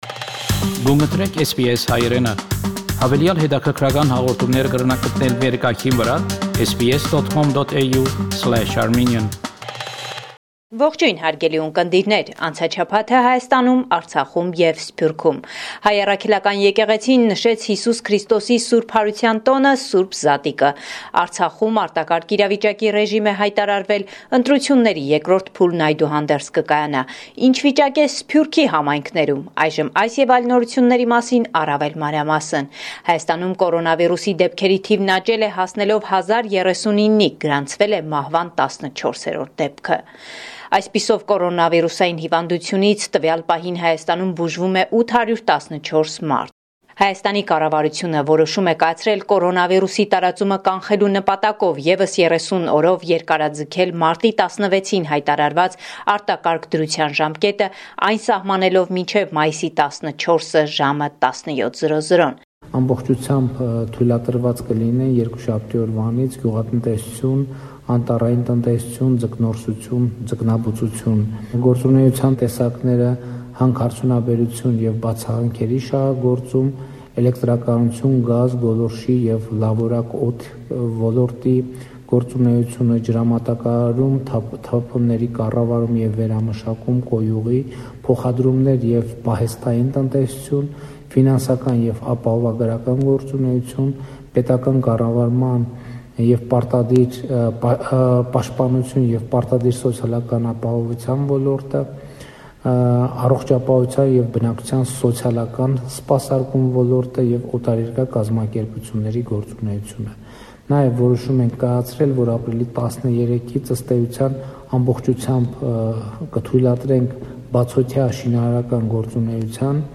Latest News from Armenia – 14 April 2020